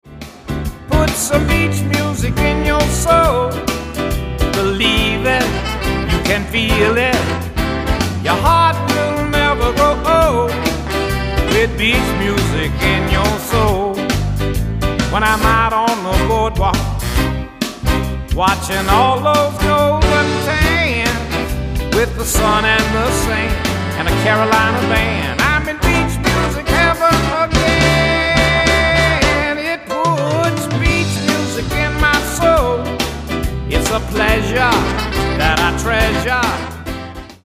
The Best in Soul, Rhythm & Blues, and Carolina Beach Music
"Bubblegum Beach" style of music.